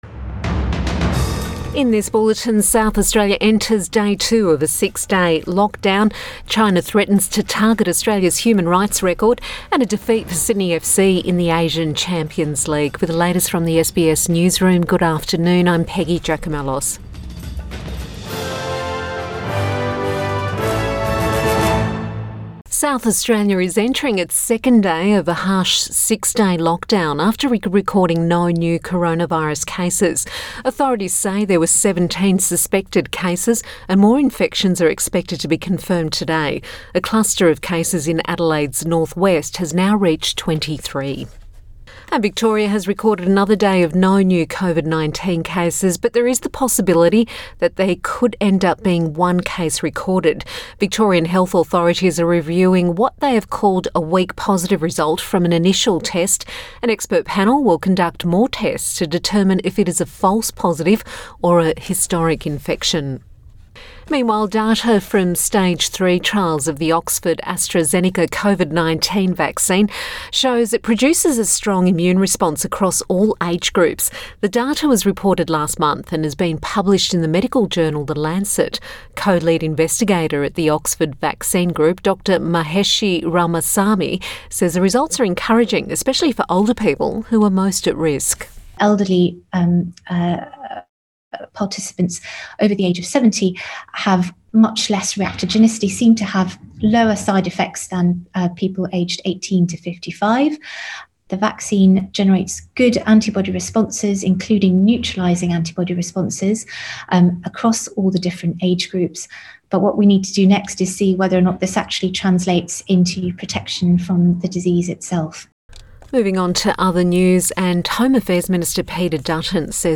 Midday bulletin 20 November 2020